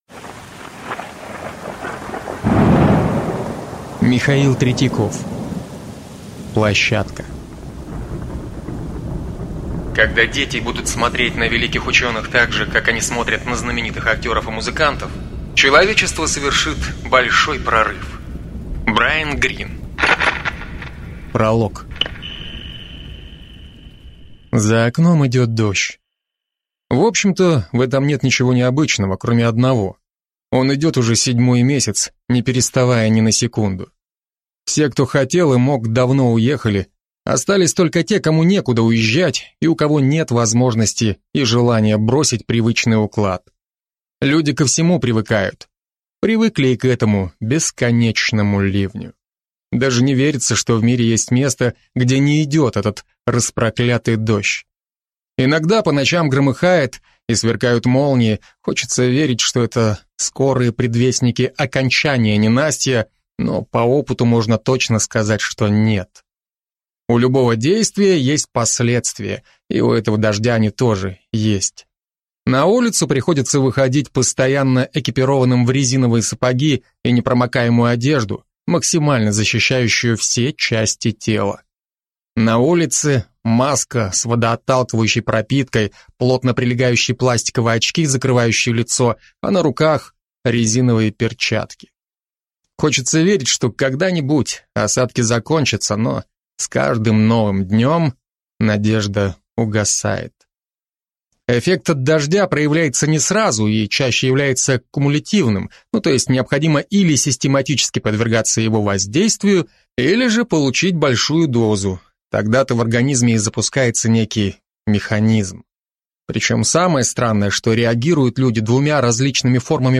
Аудиокнига Площадка | Библиотека аудиокниг